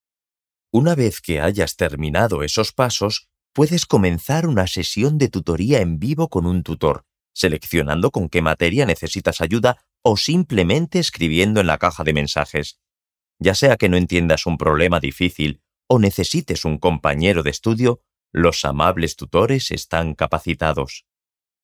Locutores españoles. Voces de locutores mayores